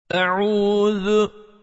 1. Tabii Med (Medd-i Tabii):
Tabii med hareke uzunluğunun iki katı uzatılır.